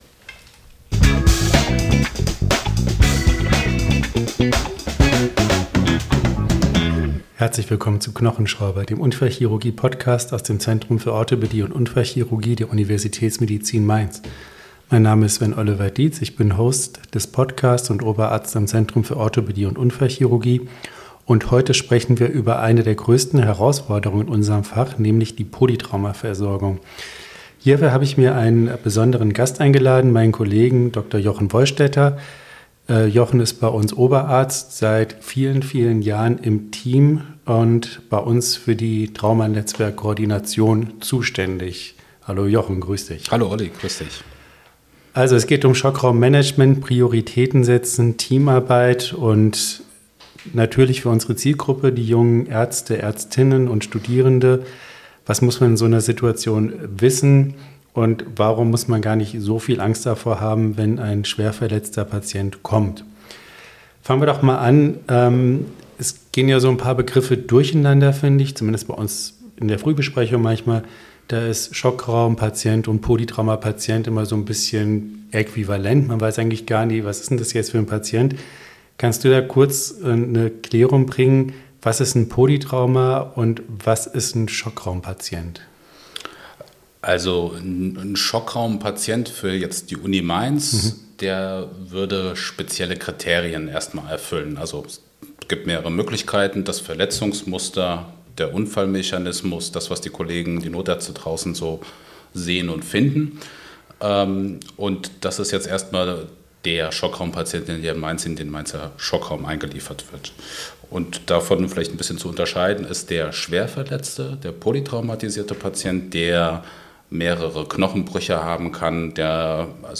Er unterhält sich jede Woche mit einem Gast.